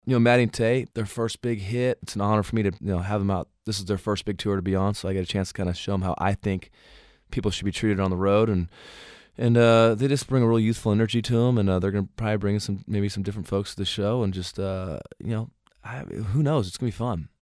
Audio / Dierks Bentley talks about bringing Maddie & Tae out on the road on the Sounds of Summer Tour.